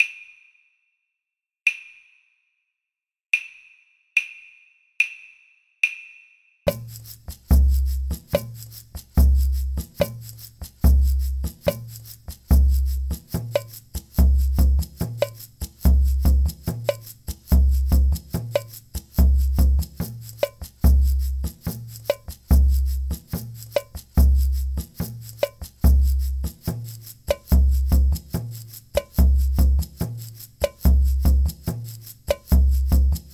Apumateriaaliksi nauhoitin kymmenen toistoa siten, että muusikkoa säestävät harmoniset ja rytmiset instrumentit. Kaikki toistot äänitettiin kotistudiossani.